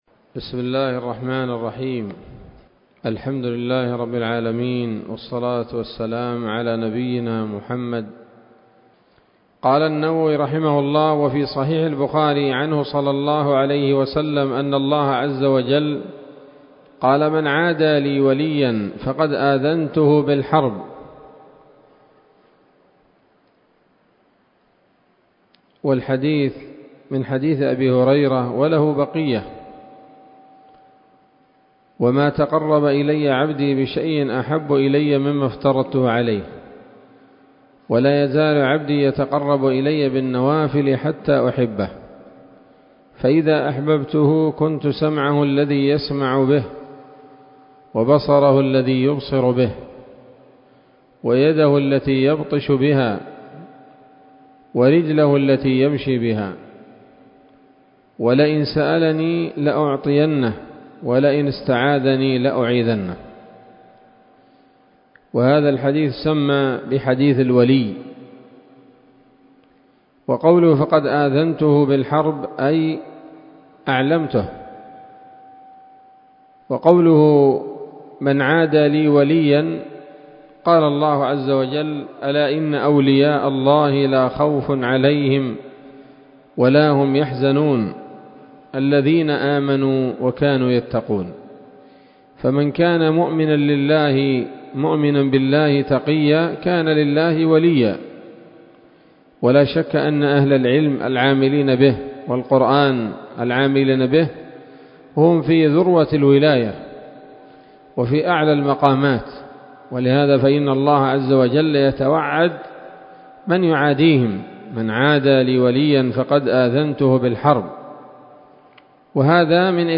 الدرس الرابع من مختصر التبيان في آداب حملة القرآن للنووي